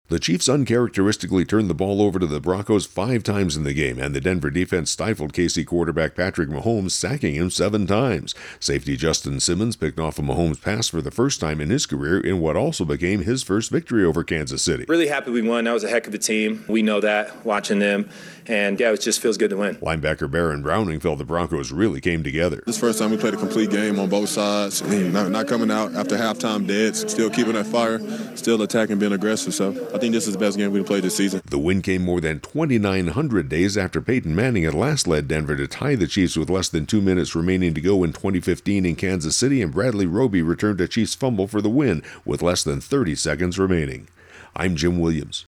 (Broncos Beat Chiefs wrap                            :46)